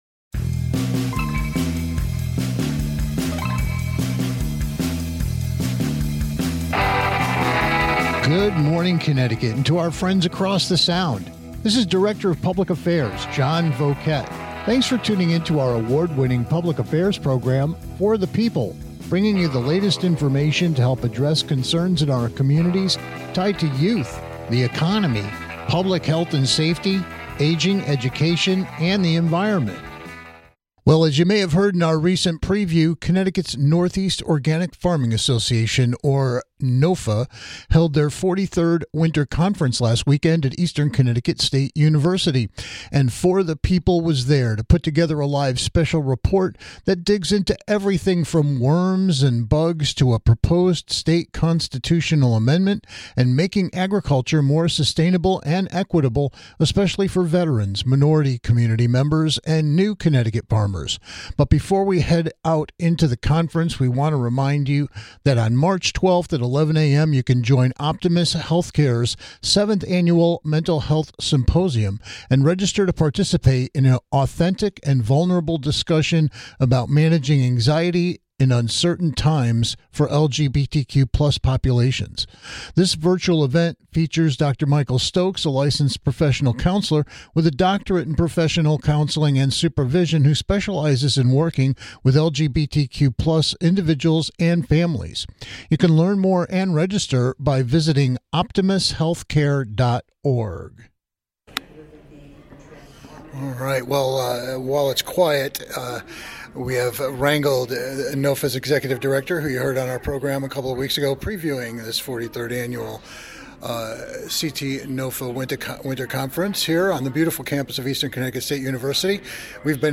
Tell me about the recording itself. Special Report: Live from CT NOFA's 43rd Winter Conference